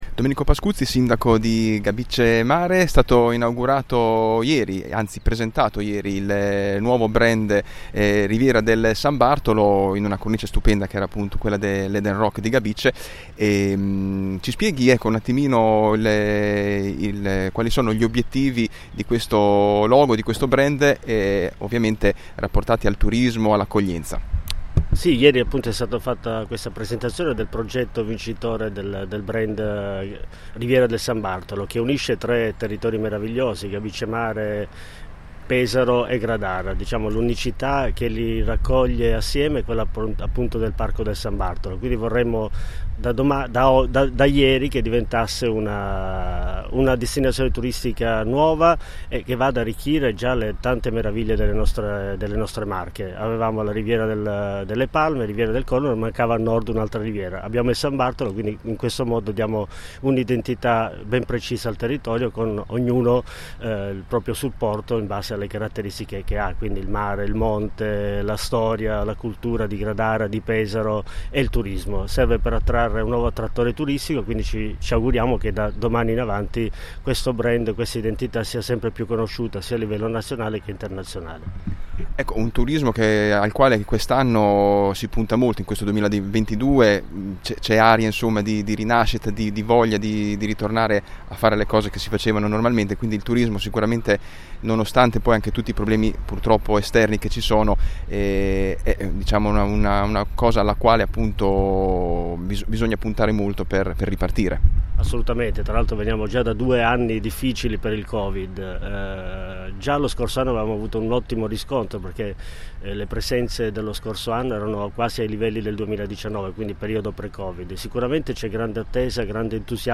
A margine della presentazione del nuovo brand “Riviera del San Bartolo” che coinvolge i comuni di Pesaro, Gabicce e Gradara, abbiamo parlato con il Sindaco di Gabicce Mare, Domenico Pascuzzi, degli obbiettivi del nuovo brand e della stagione turistica 2022 alle porte.